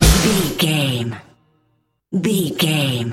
Aeolian/Minor
Fast
drum machine
synthesiser
electric piano
90s